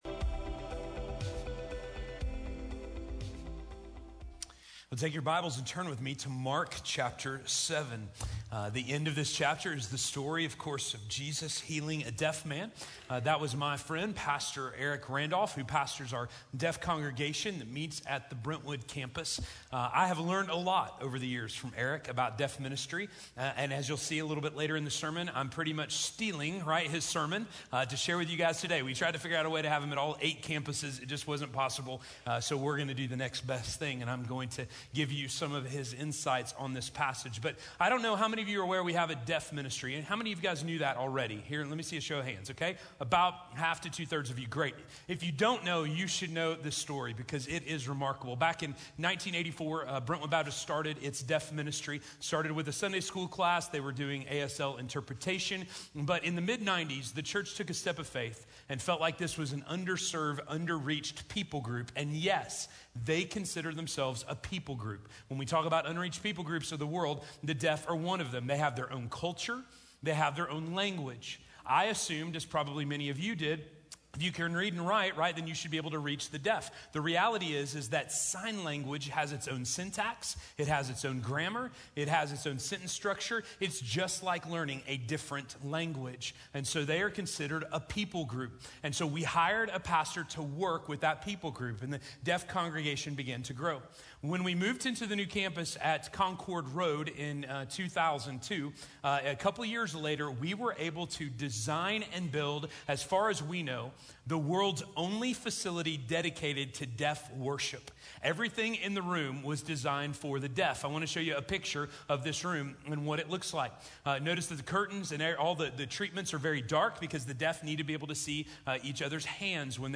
Open Eyes, Open Ears - Sermon - Station Hill